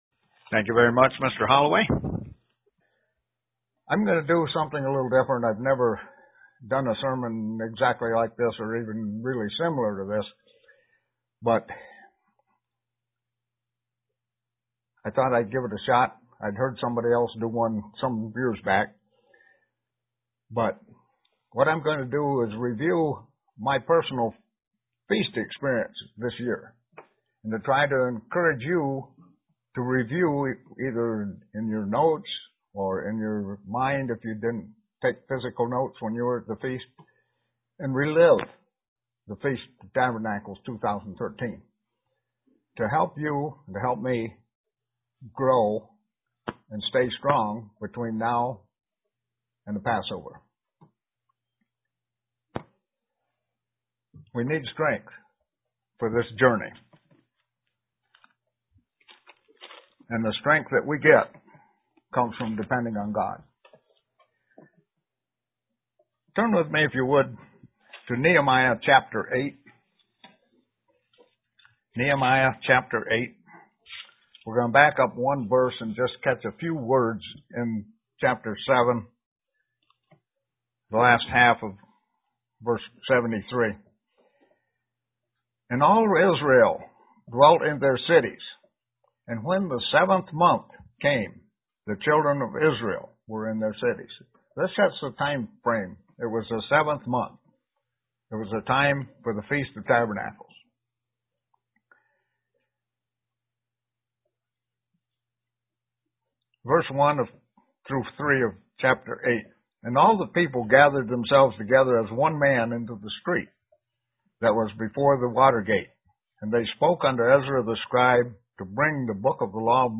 Print What I learned from the Feast of Tabernacles that I attended UCG Sermon Studying the bible?